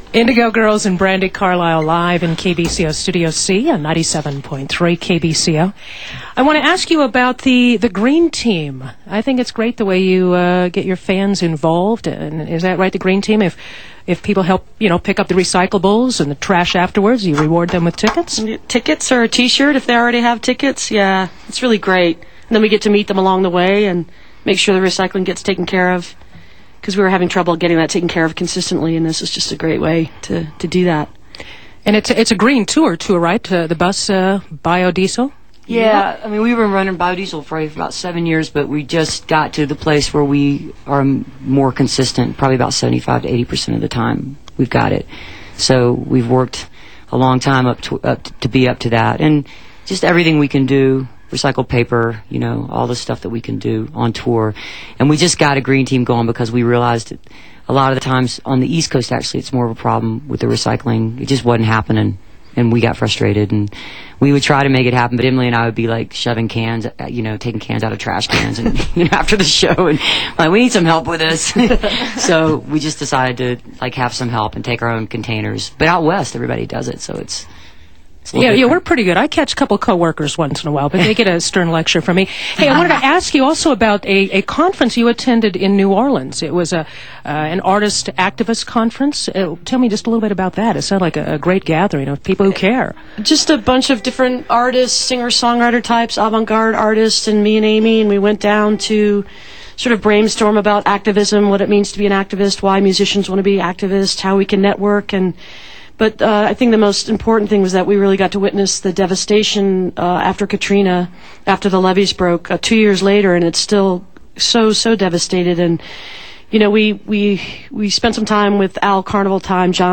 lifeblood: bootlegs: 2007-06-20: kbco - boulder, colorado (with brandi carlile)
05. interview (3:06)